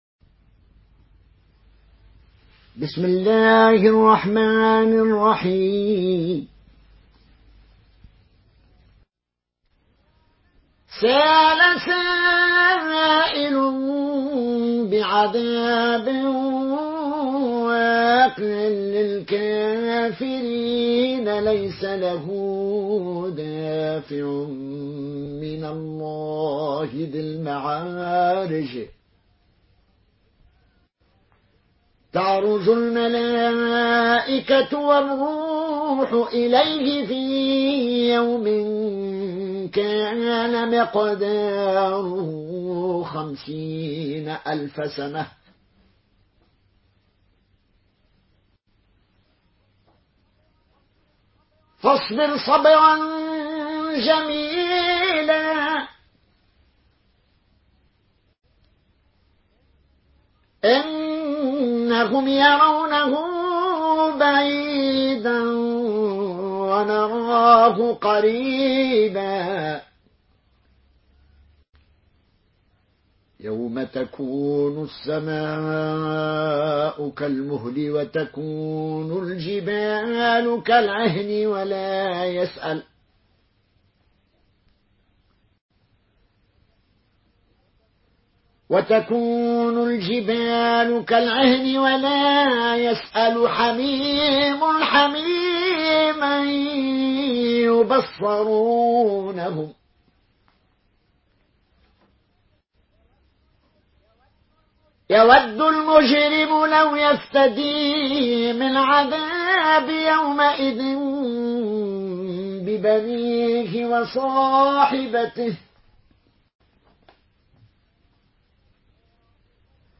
Une récitation touchante et belle des versets coraniques par la narration Qaloon An Nafi.